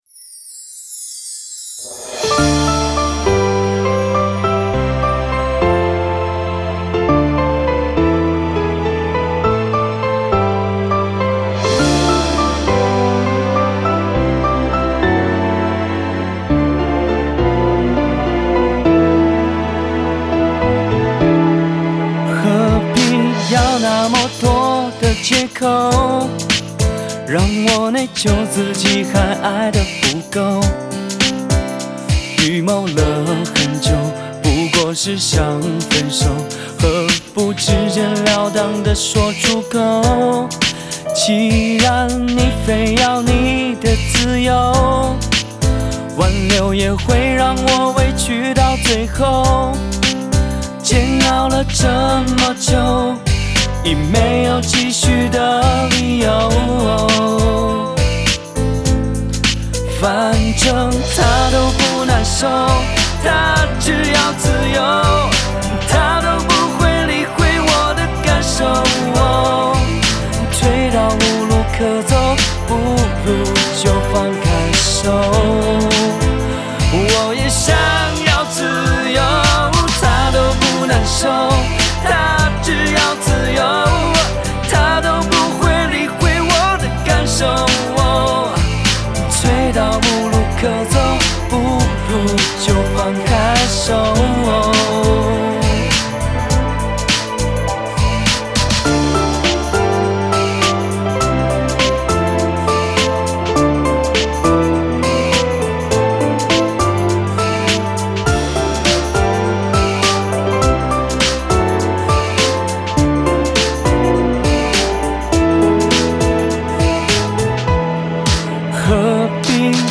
It's a new take on the traditional Tahitian drumbeat samba.